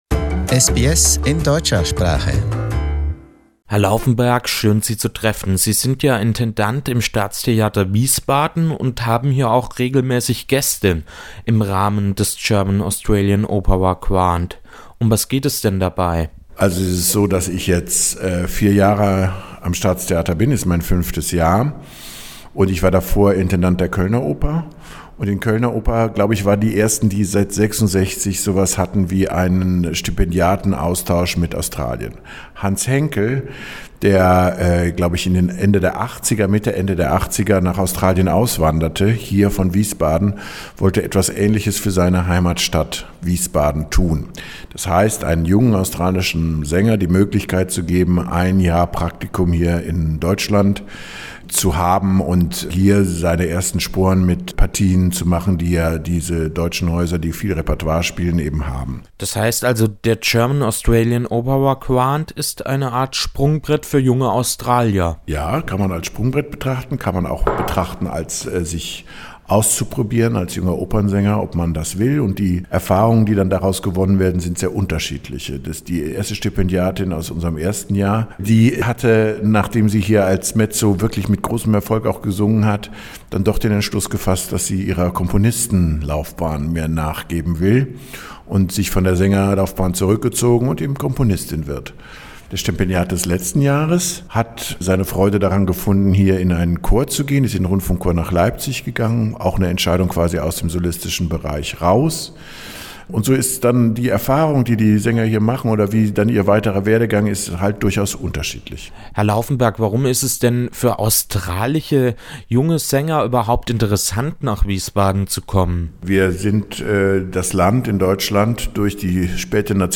German-Australian Opera Grant: An interview